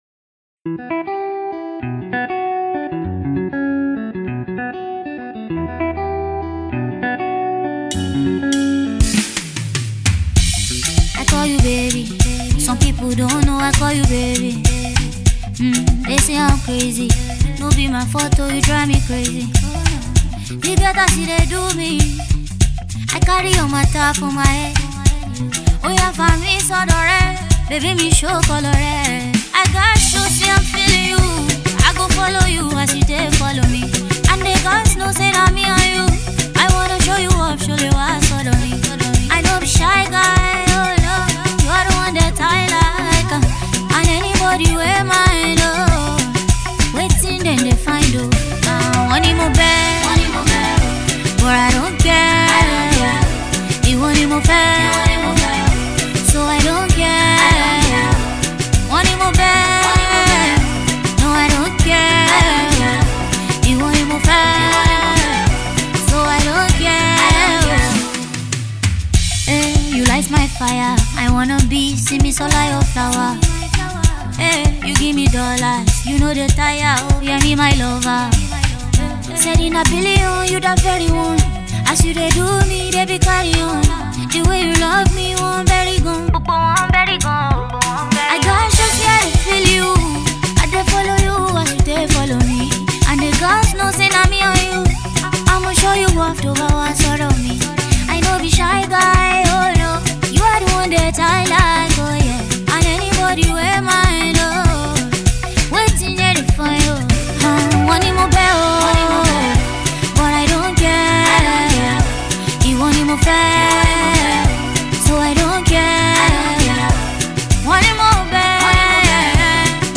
Vocal Diva
hit single